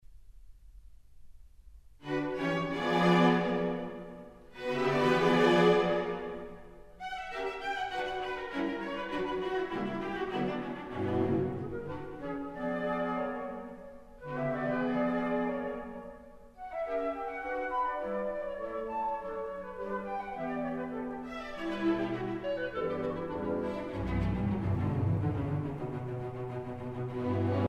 Allegro non troppo
si minore
bt. 19-29 + flauti e clarinetti 2a   esempio 2a di partitura (formato PDF)
esempio 2a orchestrale (formato MP3)